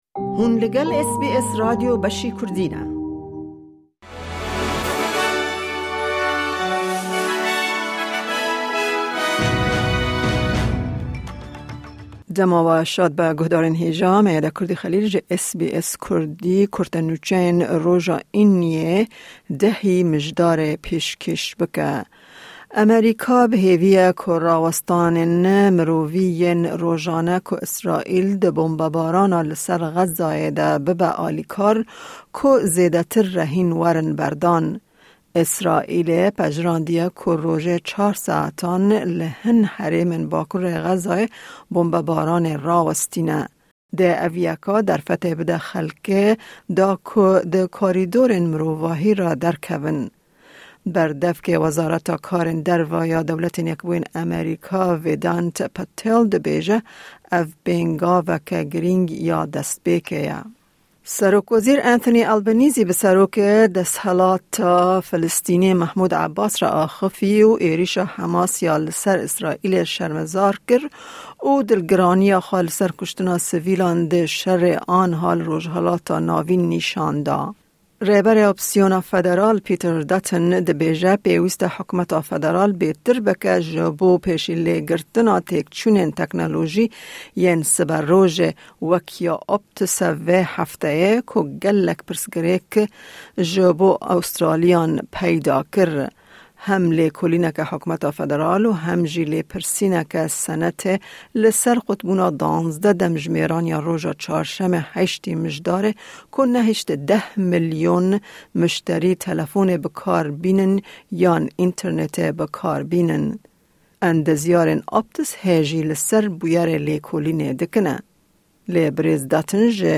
Di vê kurte bultena nûçeyan de: Hêviyên lihevkirinek ji bo rawestandina bombebarana Îsraîlê ya li ser Xezeyê dê alîkariya azadkirina rehînan bike… Rêberê opozîsyona Federal dibêje pêwîste hukumeta federal bêtir bike da pirsgirêkên wek Optus çê nebin...